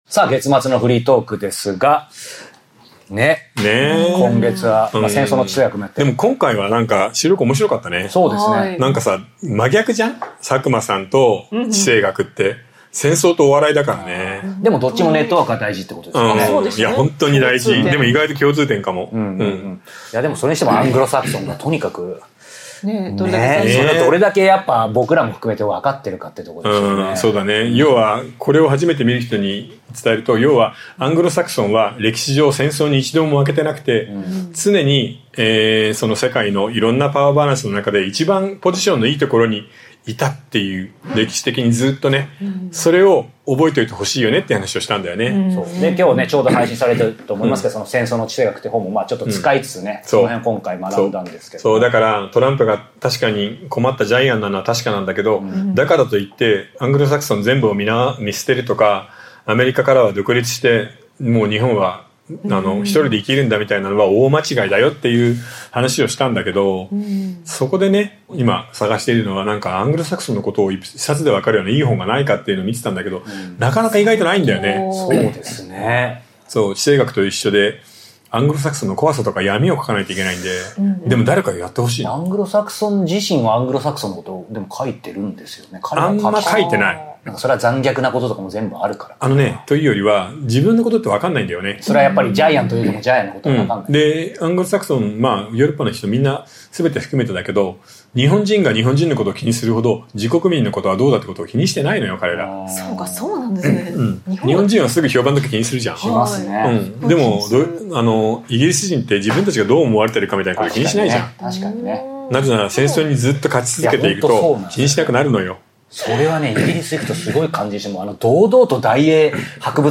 7月のフリートークスペシャル！
Audio Channels: 1 (mono)